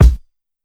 Kick (Disco).wav